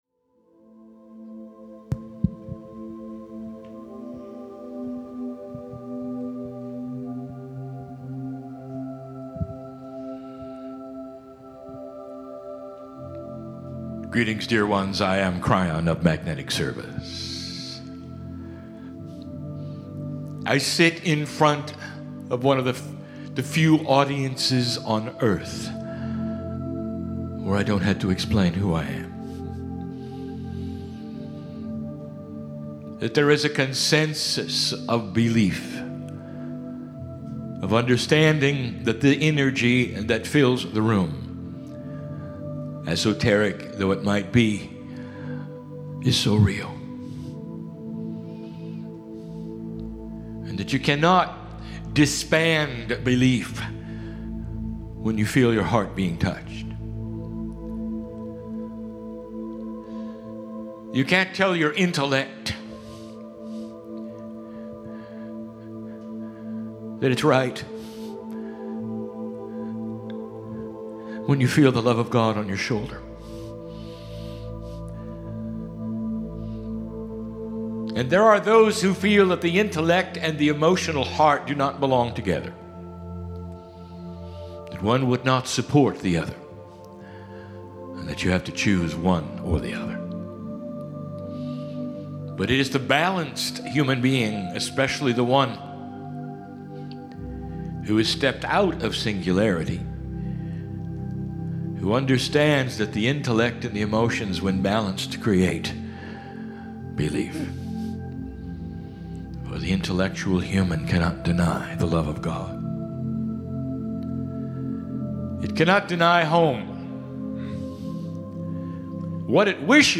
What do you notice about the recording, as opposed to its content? INSTRUCTIONS: 63.4 megabytes 42 minutes High-quality Stereo - MP3 Filename: "laguna.mp3" PC - Right-click the left image to download the file.